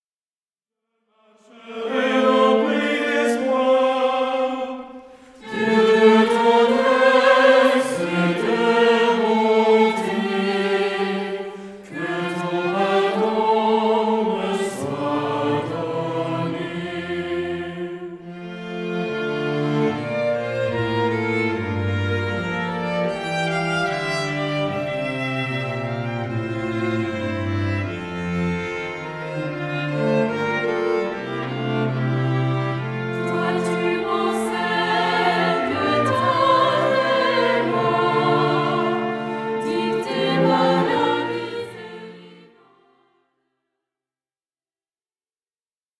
Format :MP3 256Kbps Stéréo